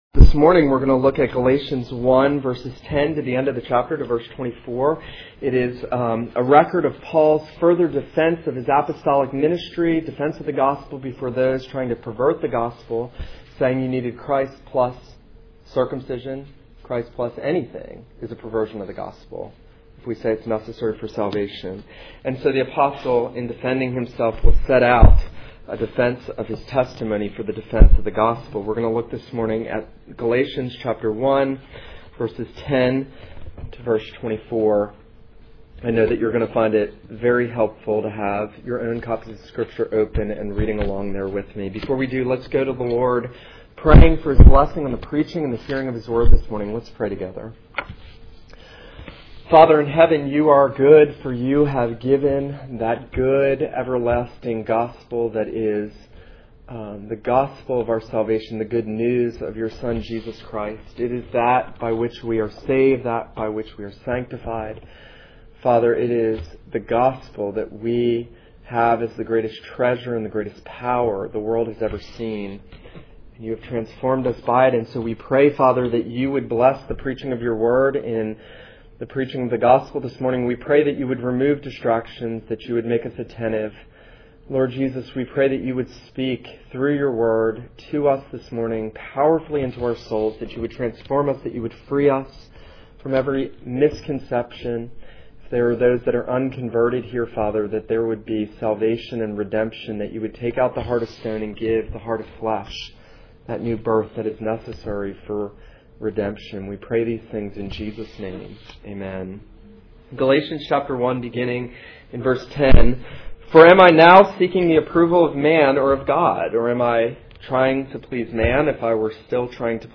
This is a sermon on Galatians 1:10-24.